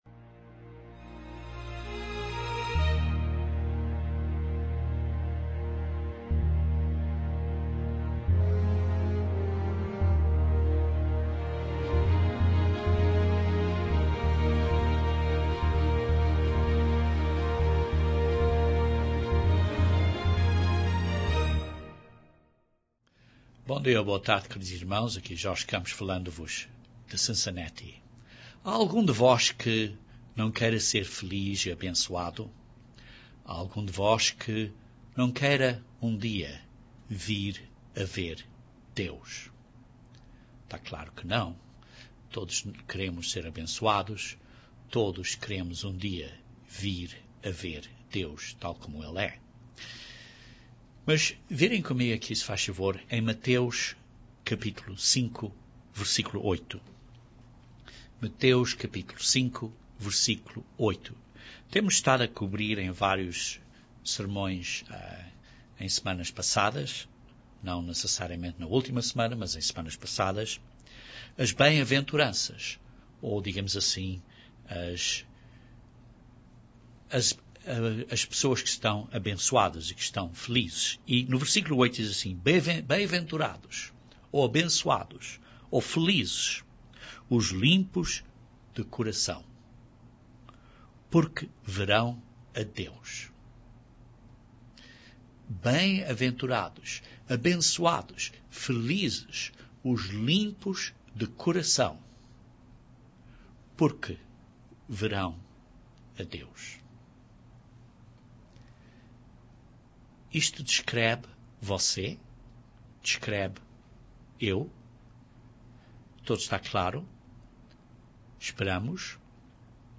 Given in Maloca de Moscou